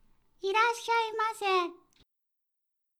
ボイス
キュート挨拶